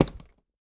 CLASP_Plastic_Open_stereo.wav